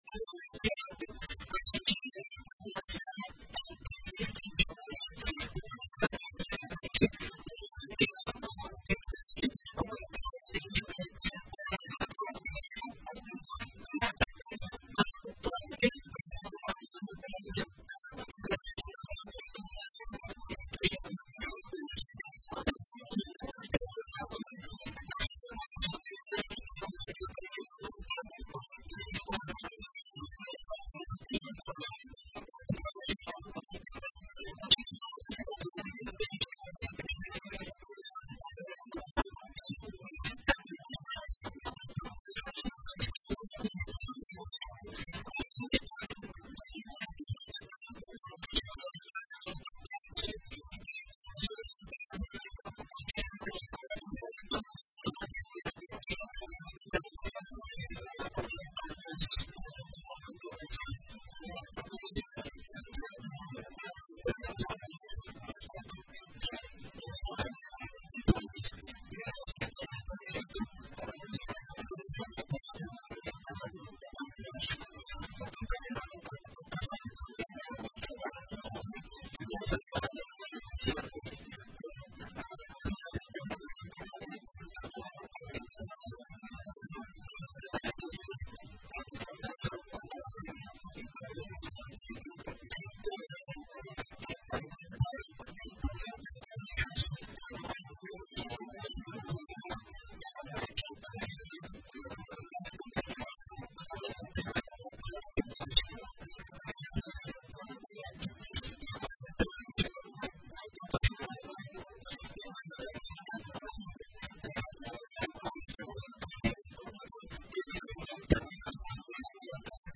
Em entrevista à RPI nesta manhã, o secretário de Meio Ambiente, Yuri Pilissão, disse que a análise envolve temas importantes, como áreas de risco geotécnico e de inundação, áreas de preservação permanente, qualidade da água dos arroios urbanos, mobilização social e drenagem de águas pluviais.